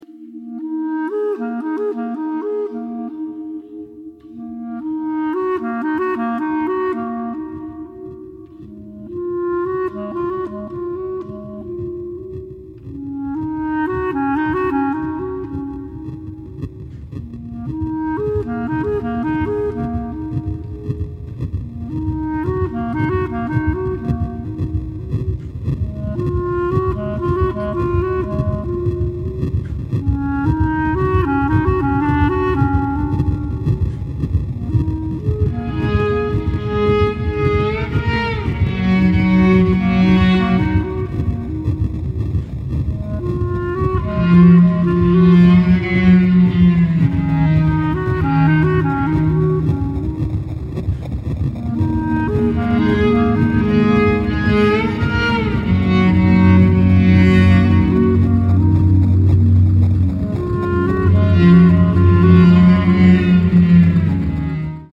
Recorded at Big Orange Sheep, Brooklyn